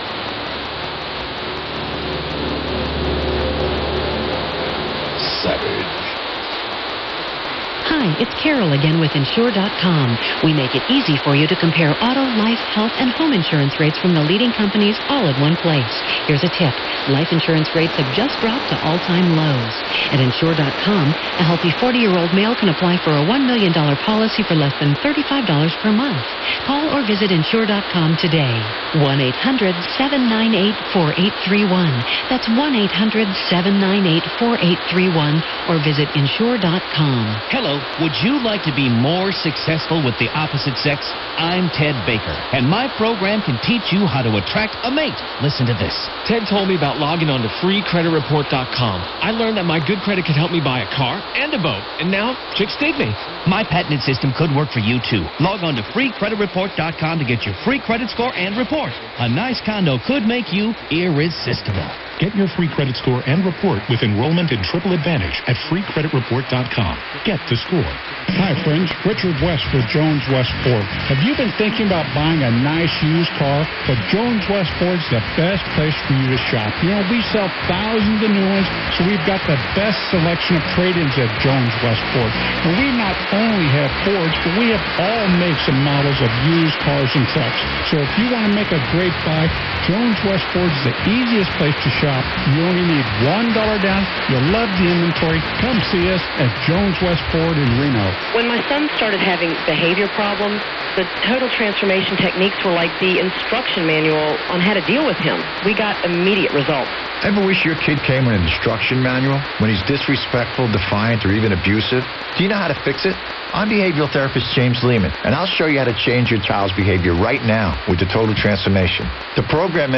> This recording was made in the few minutes spanning pattern change/power
> fading, which is audible on the recording as the IBOC noise fades up and
IBOC ceases at 2:14 into the recording.
> The receiver was a WinRadio, using synchronous detection, at 11 kHz
KKOH-KCBC-IBOC.mp3